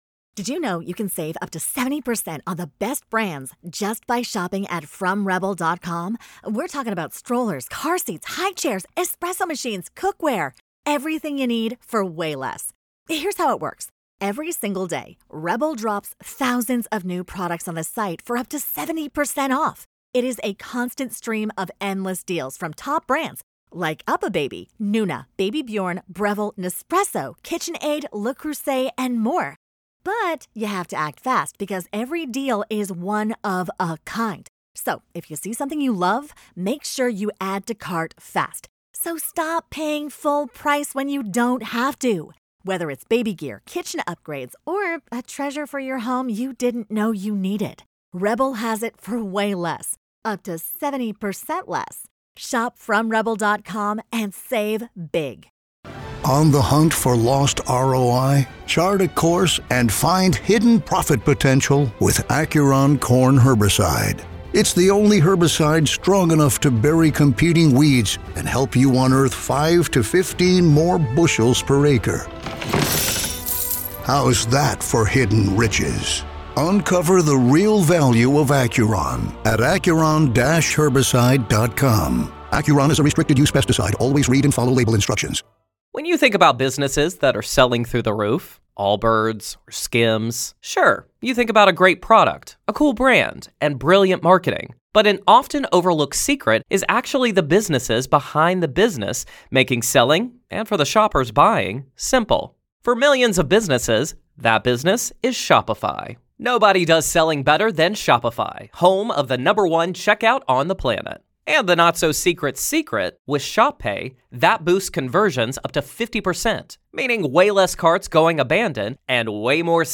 This segment balances unsettling details with the hosts’ banter, bringing you that perfect mix of chills and laughs.